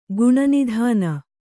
♪ guṇanidhāna